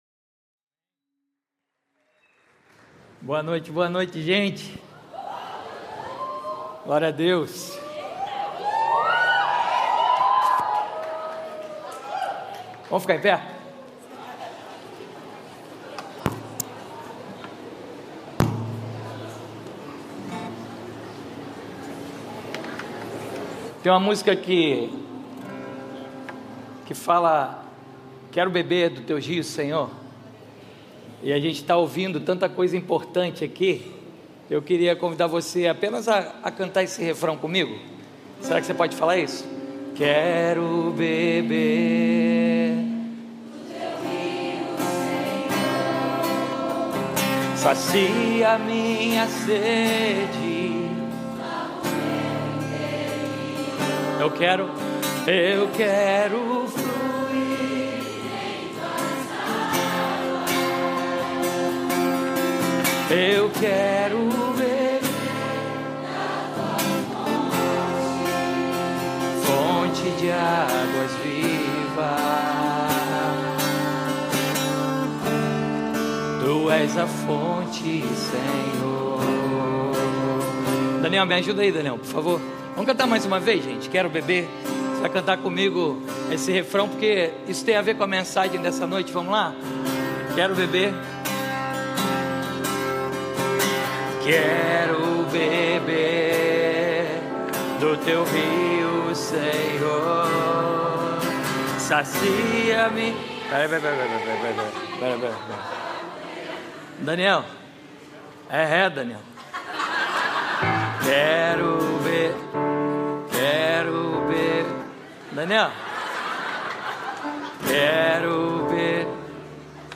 Mensagem
como parte da série YTH CON 25 na Igreja Batista do Recreio.